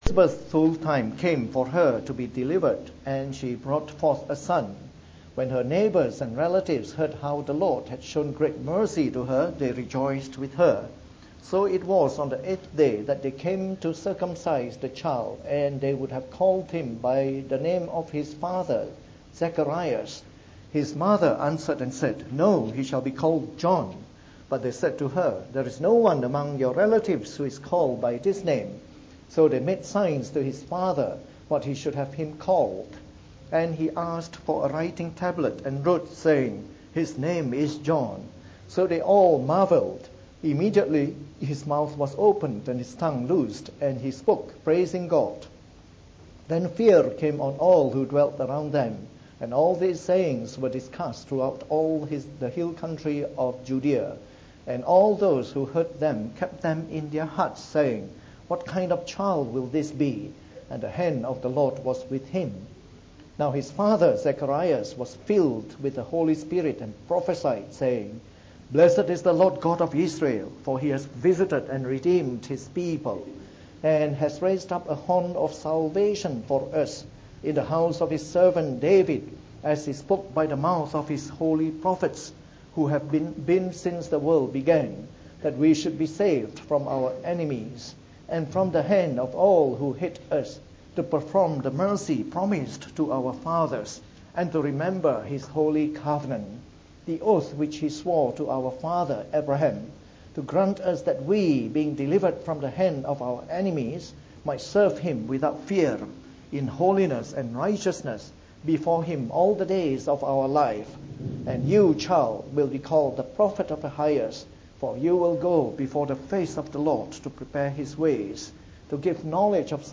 From our new series on the “Gospel According to Luke” delivered in the Evening Service.